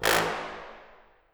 Metro Fat Horn 2.wav